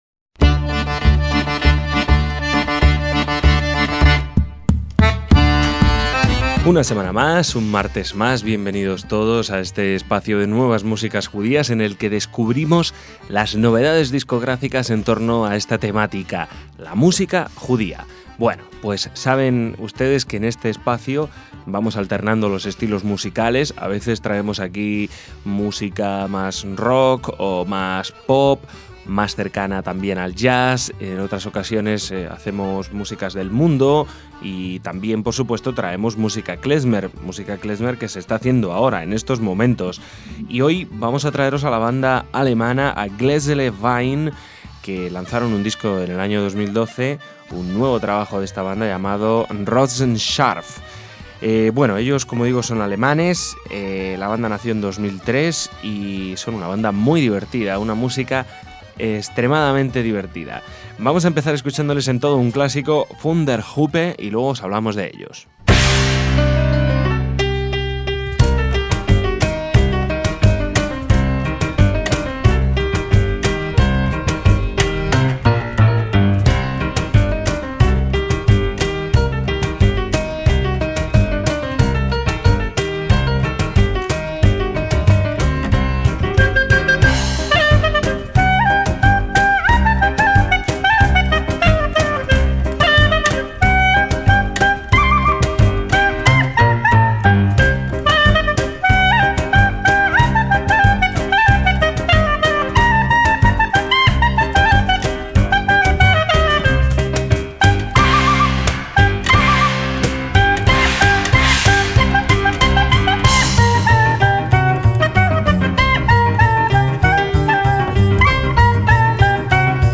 cuarteto alemán de música klezmer y buen humor
clarinete
acordeón
percusiones y guitarra
contrabajo